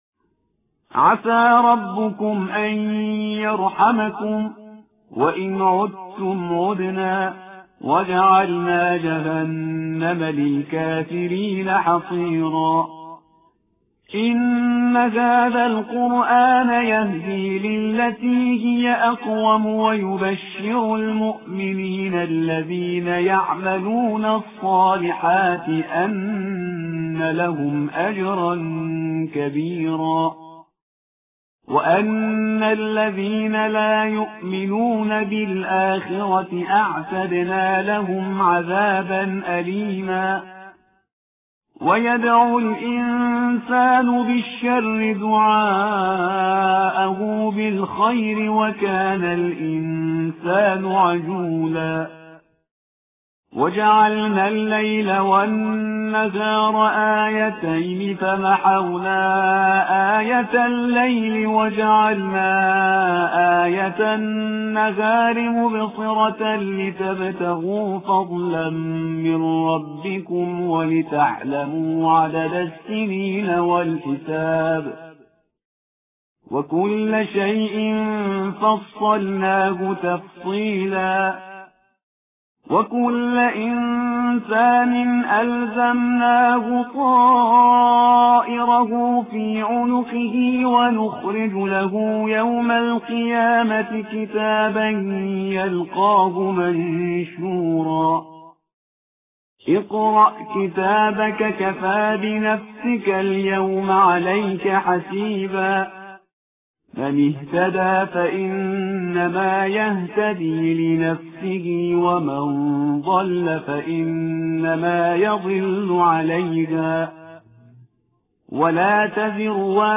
پایه پنجم،درس یک ،تلاوت سوره اسراء